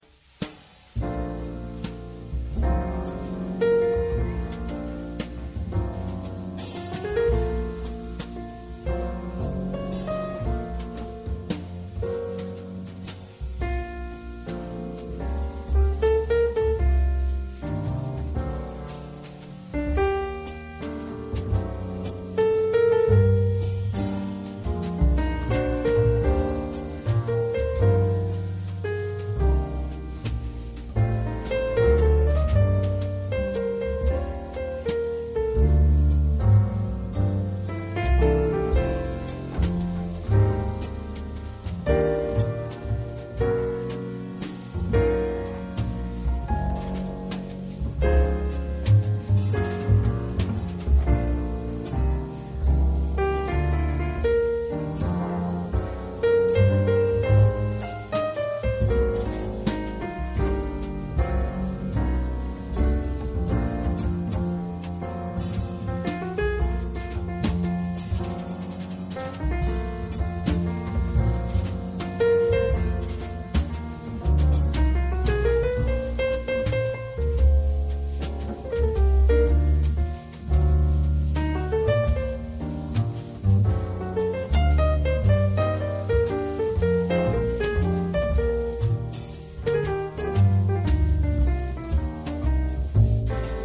piano
contrabbasso
batteria
lenta ballad